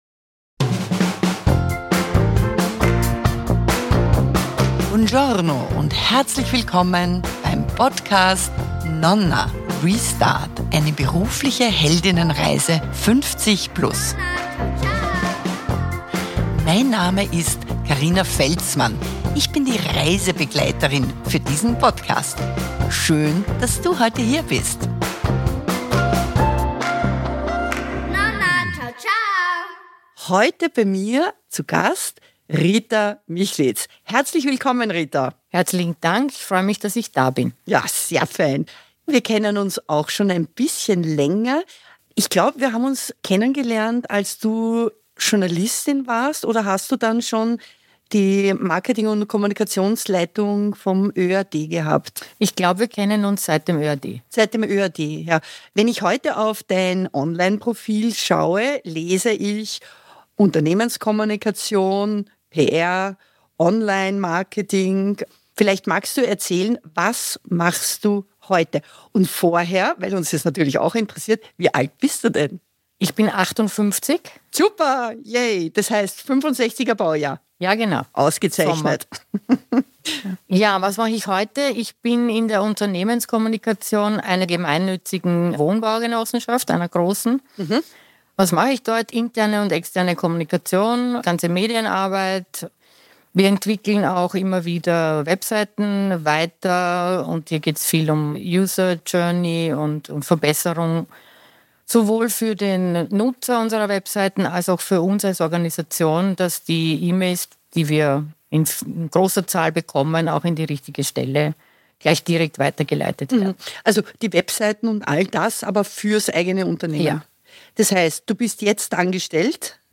interviewt zur beruflichen Held*innenreise